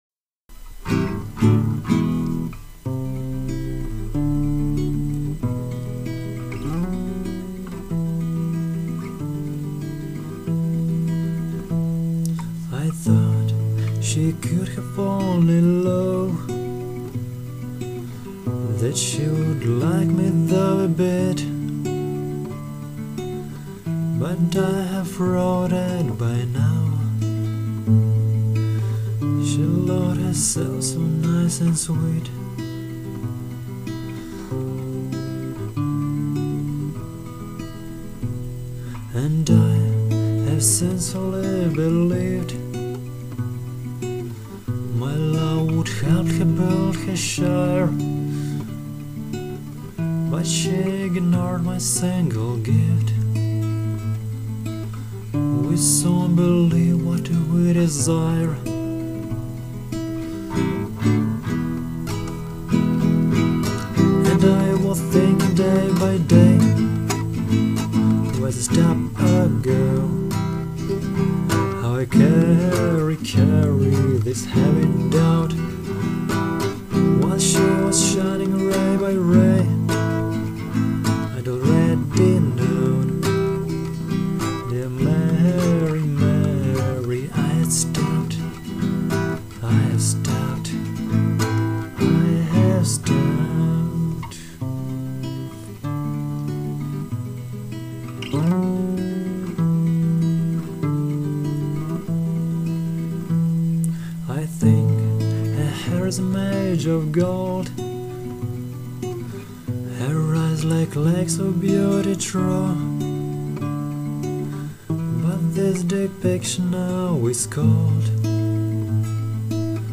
Рубрика: Поезія, Авторська пісня
Гарно звучить... чуттєвість, ритміка, текст...